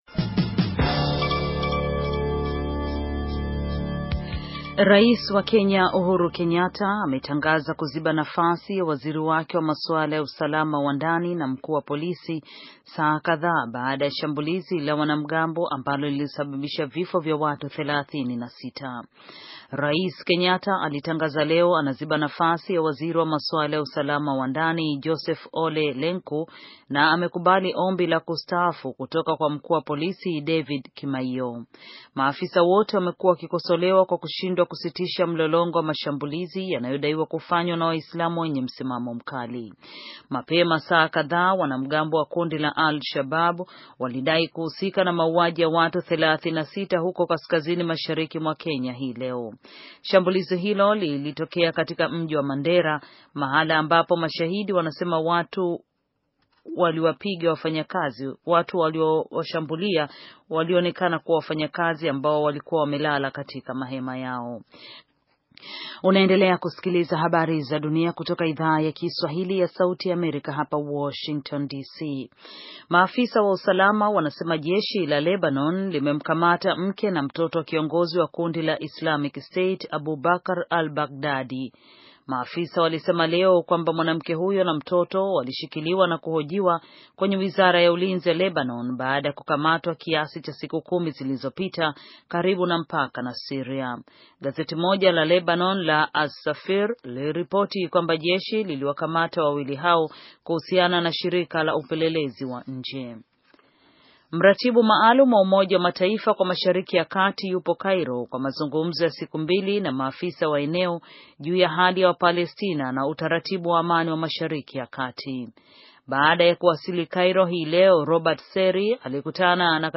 Taarifa ya habari - 6:22